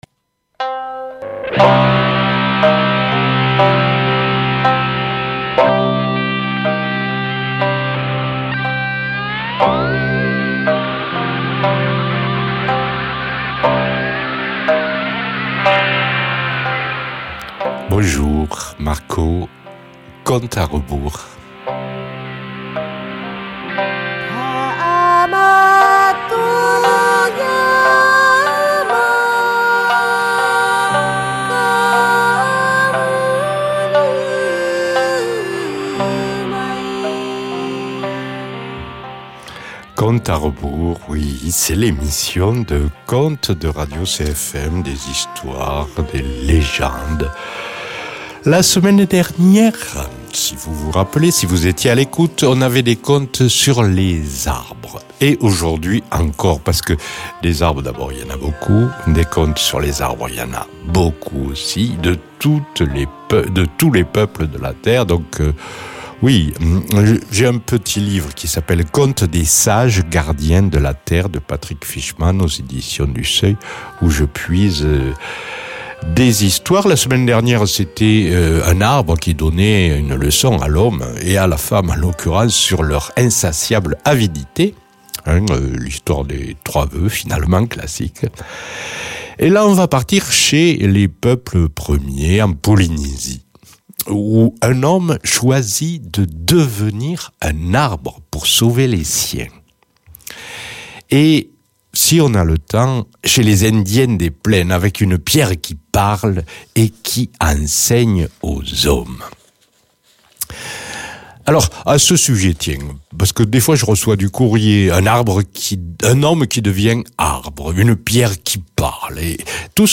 Musiques : Chants traditionnels polynésiens, chant de l’arbre à pain.